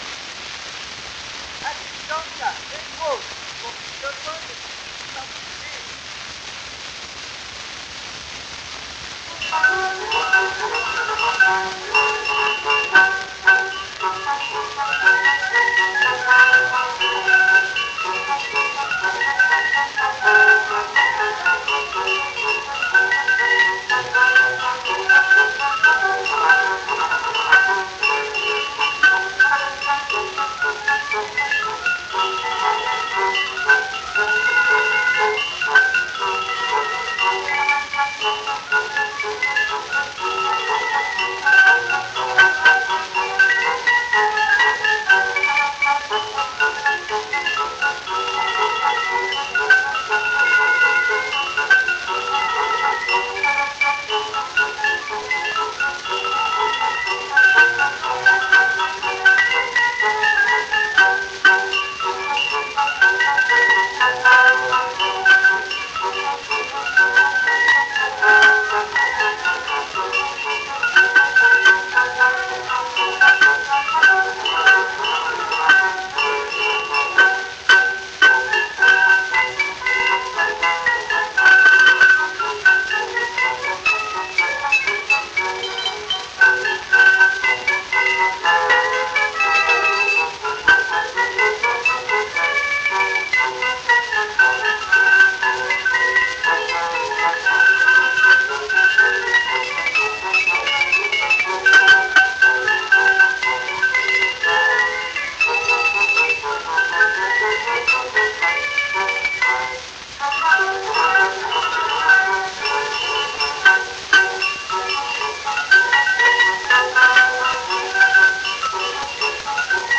Xylophon mit Bläserbegleitung.